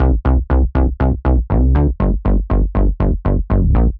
Index of /musicradar/french-house-chillout-samples/120bpm/Instruments
FHC_SulsaBass_120-C.wav